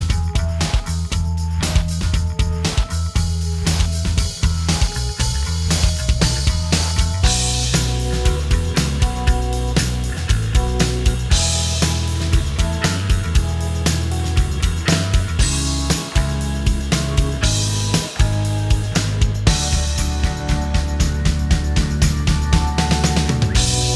Minus All Guitars Indie / Alternative 4:21 Buy £1.50